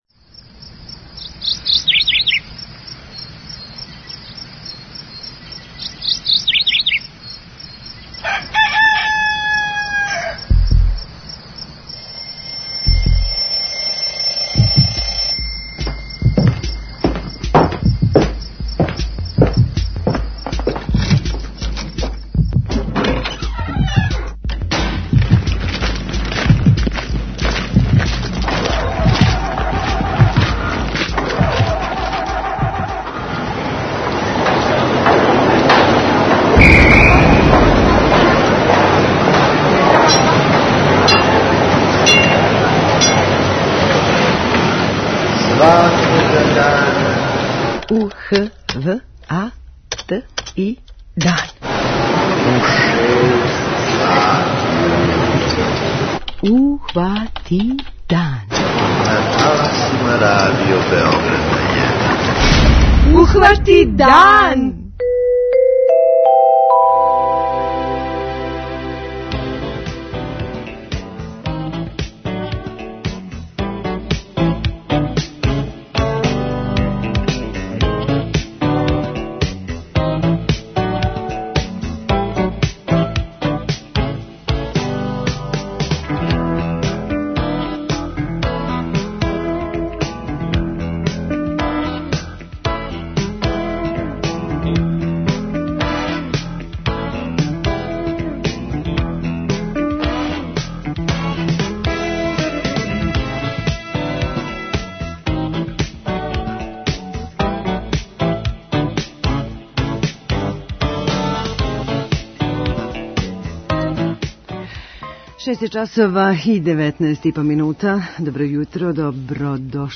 преузми : 29.21 MB Ухвати дан Autor: Група аутора Јутарњи програм Радио Београда 1!